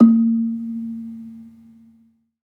Gambang-A#2-f.wav